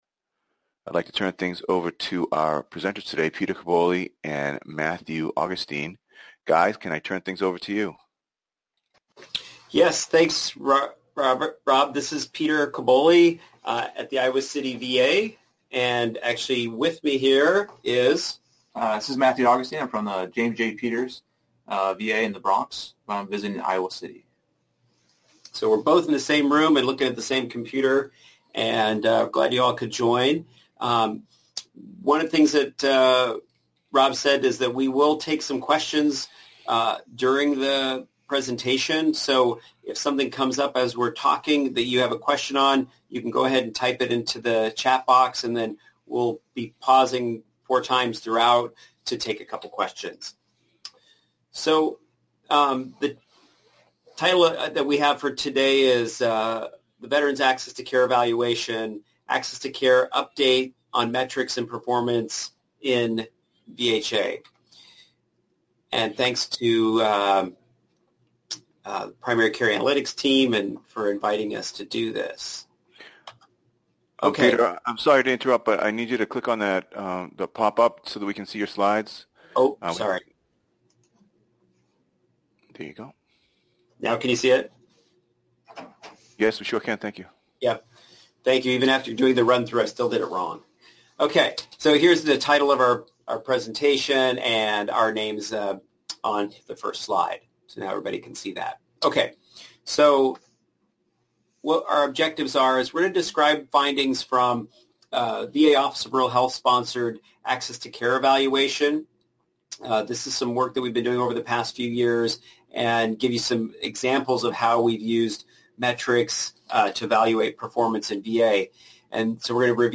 Description: This webinar will cover the findings from a series of inter-related projects funded by the Office of Rural Health to improve the measurement and reporting of access to care. Areas covered include primary care (i.e., panel size and extended hours clinics), use of nurse practitioners in VA call centers, secure messaging, E-consults, and novel ways to measure access to care.